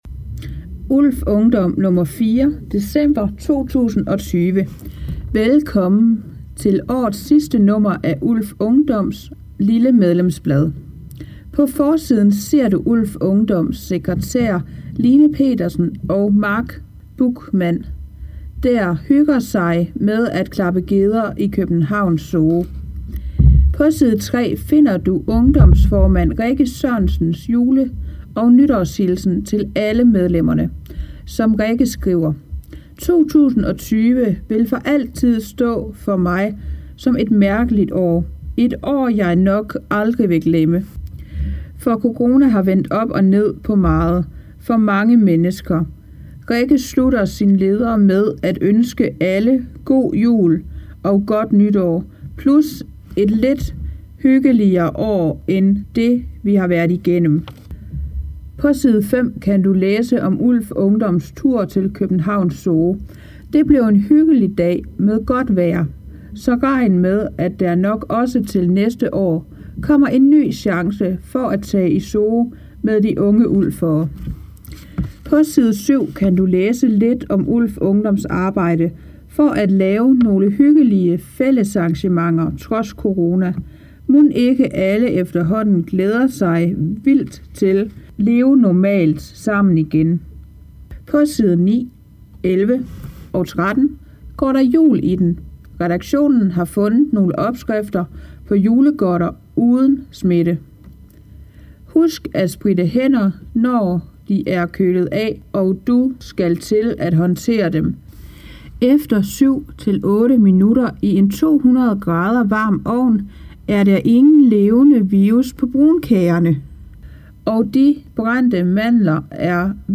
ULF Ungdom nr. 4 – 2020 – Speaket kommer hér: